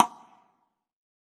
ECONGASLP.wav